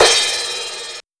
• Drum Crash Sound C Key 05.wav
Royality free crash tuned to the C note. Loudest frequency: 4097Hz
drum-crash-sound-c-key-05-ooN.wav